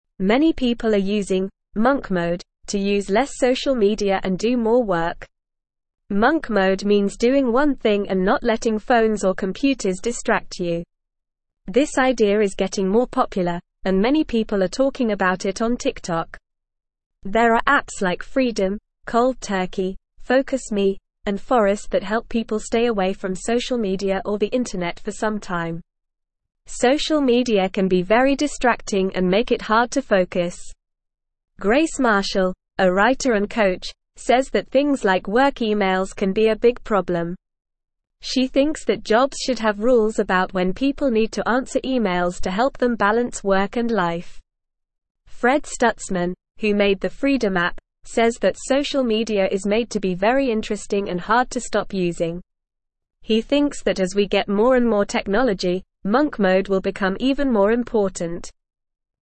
Normal
English-Newsroom-Beginner-NORMAL-Reading-Monk-Mode-Using-Less-Social-Media-Doing-More-Work.mp3